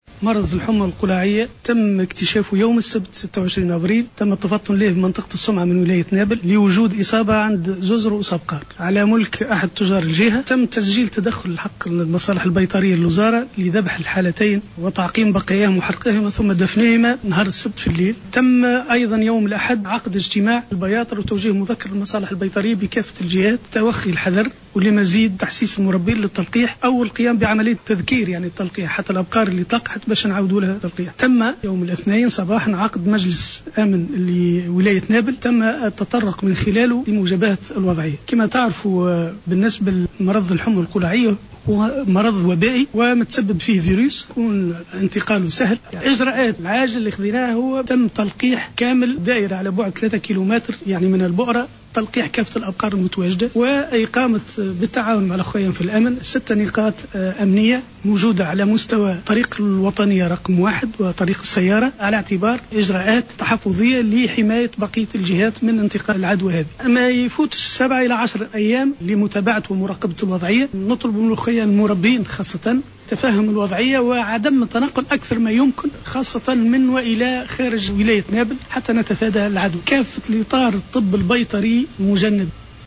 محمد العكرمي الحامدي - والي نابل تحميل المشاركة علي Play